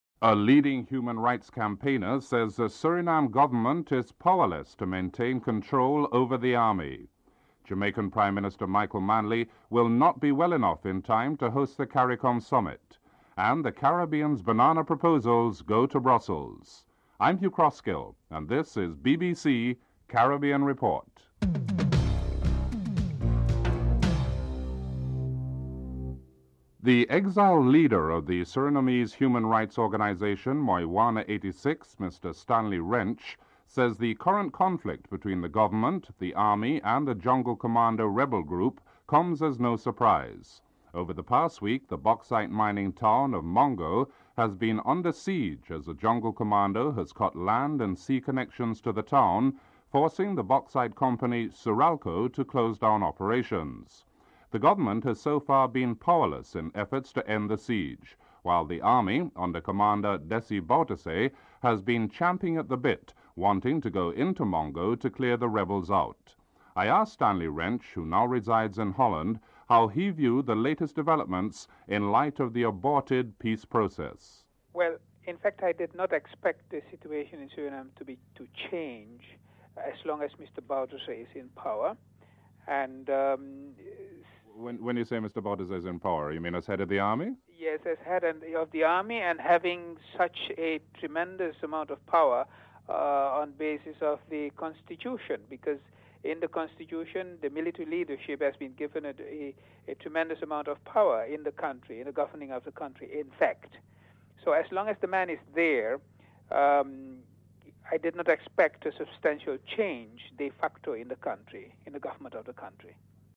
1. Headlines (00:00-00:25)
5. Financial News (09:04-09:59)